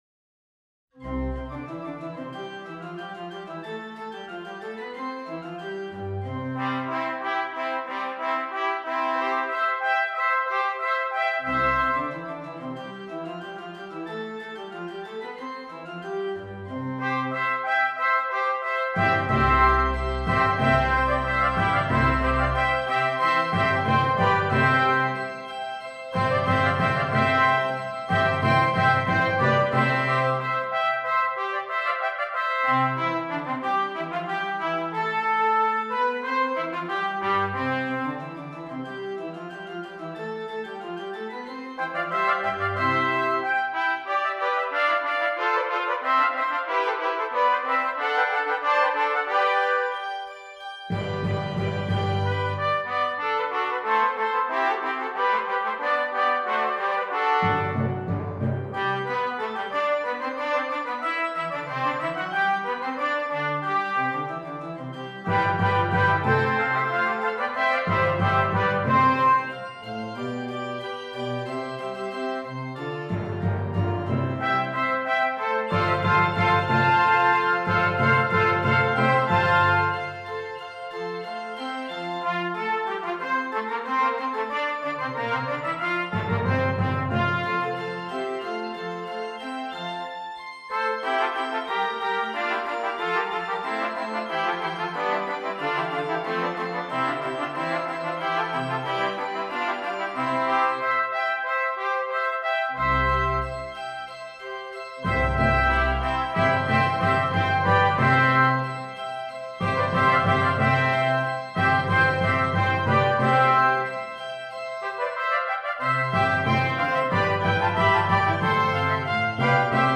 4 Trumpets, Organ and Timpani
This is a arrangement for 4 trumpets, organ and timpani.